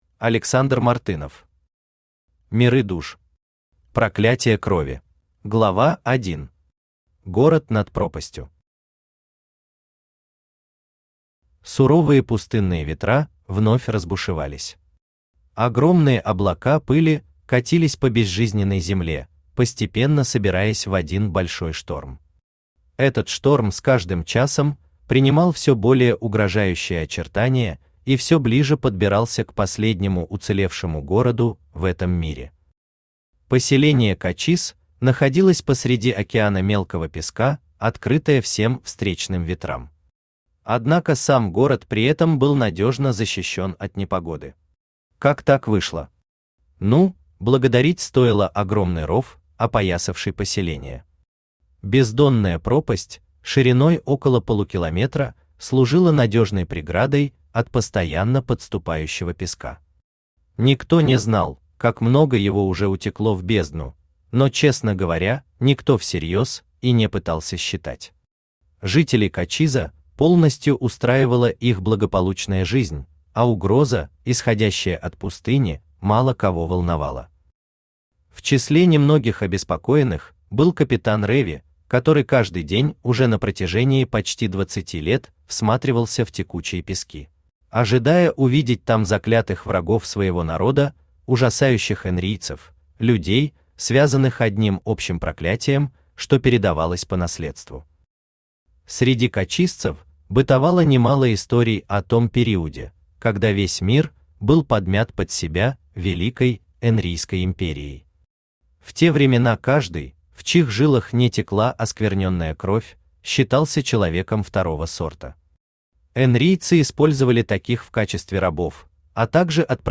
Аудиокнига Миры душ. Проклятие крови | Библиотека аудиокниг
Проклятие крови Автор Александр Мартынов Читает аудиокнигу Авточтец ЛитРес.